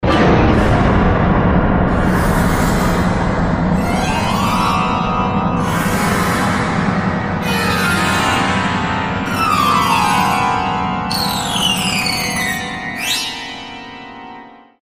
scary-noises-ringtone_14159.mp3